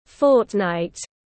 Nửa tháng tiếng anh gọi là fortnight, phiên âm tiếng anh đọc là /ˈfɔːt.naɪt/
Fortnight /ˈfɔːt.naɪt/